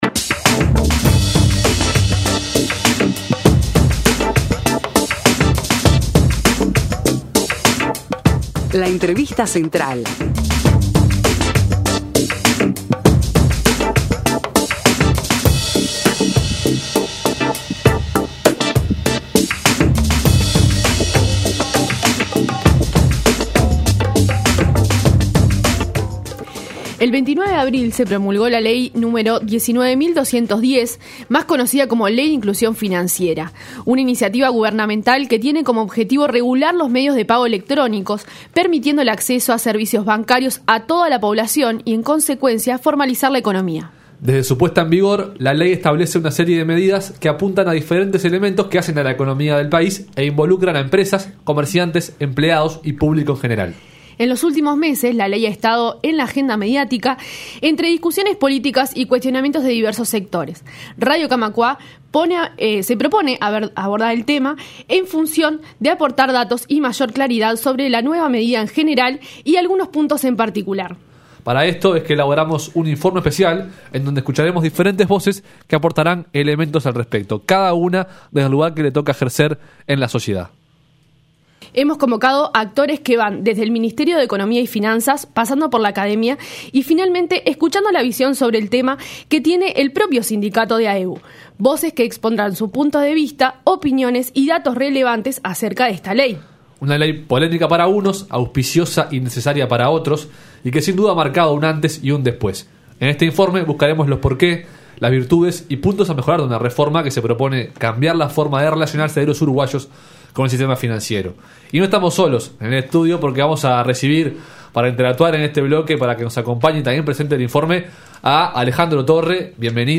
Informe Especial: Ley de inclusión financiera